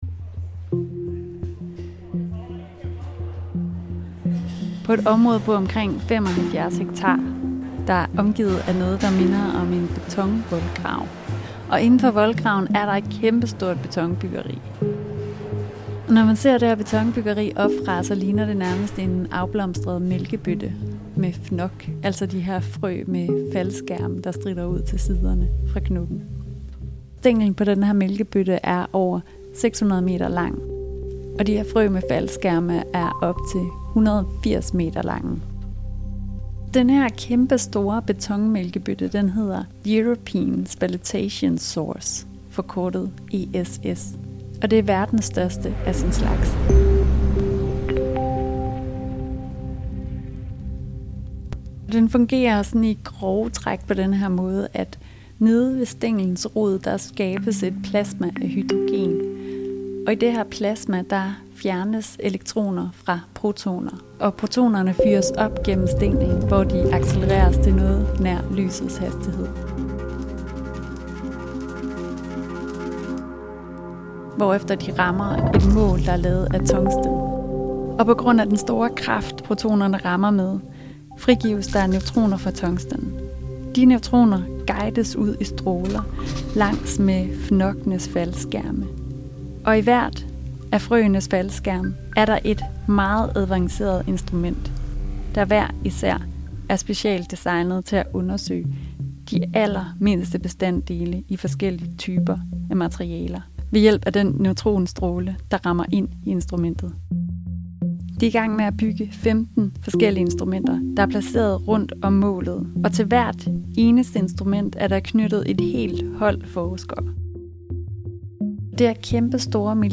Her vil Protoner fyres, med lyset hastighed, ind i et mål, hvorfra neutroner frigives og guides ud til 15 af verdens mest hightech instrumenter. Forskningen der her vil finde sted, når det står færdig i 2026, vil revolutionere videnskaben og booste menneskets udvikling. Lyden af Videnskab er taget med Foreningen Danske videnskabsjournalister på en guidet rundtur på European Spallation source (ESS) og forsøger, i denne episode, at give et indblik i dette gigantiske fysikeksperiment, og hvordan det vil kunne ændre verden.